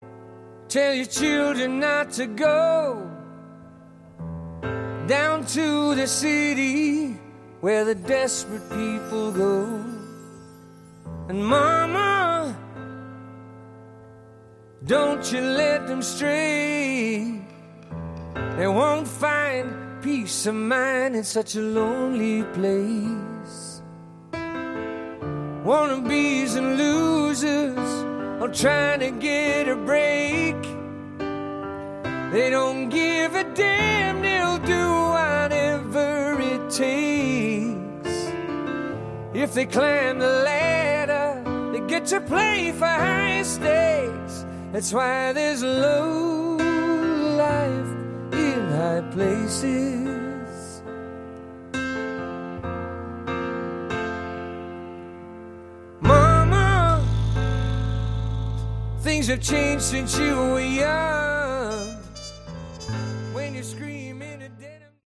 Category: Hard Rock
guitar, keyboards, backing vocals